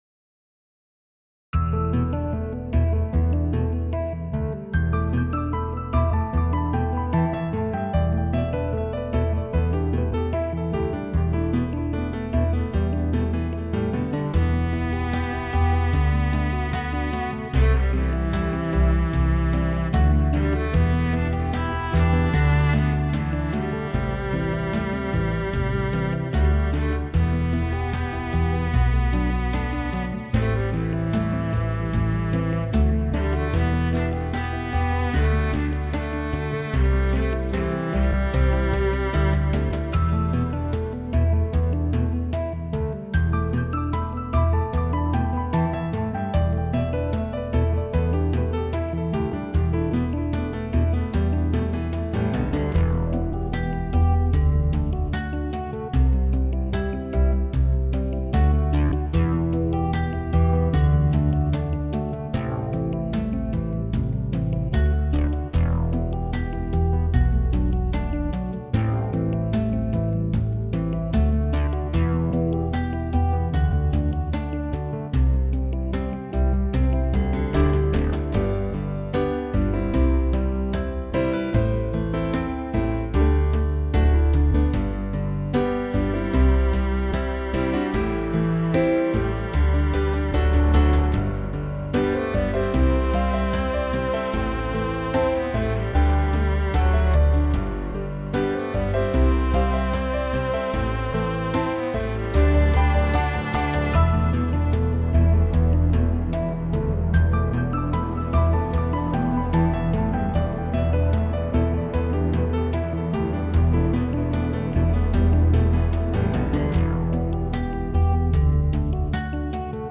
Playback-Audio (part of the pack):